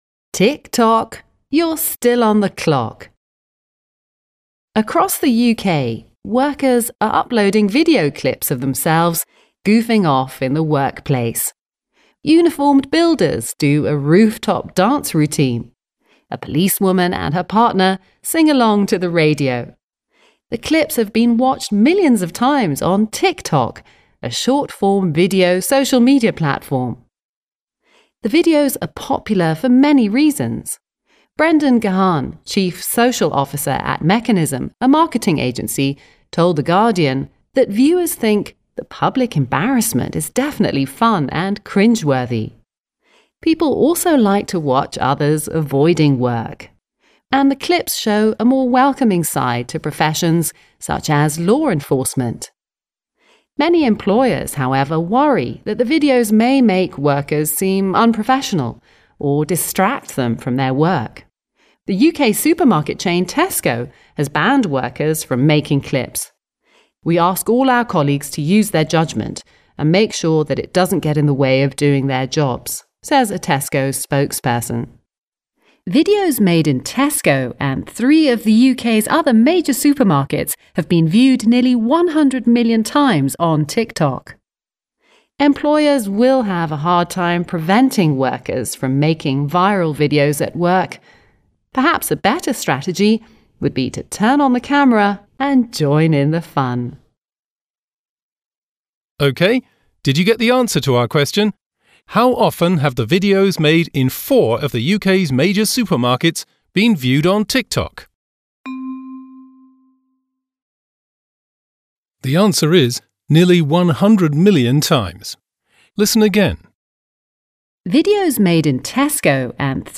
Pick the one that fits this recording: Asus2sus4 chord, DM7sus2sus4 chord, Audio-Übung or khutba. Audio-Übung